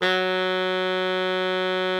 bari_sax_054.wav